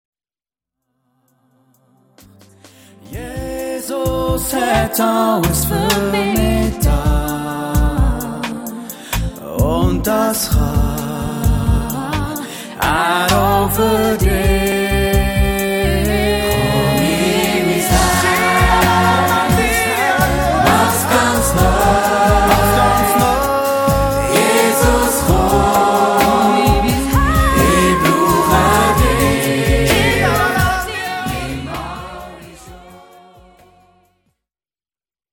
alle Leadsänger und Chor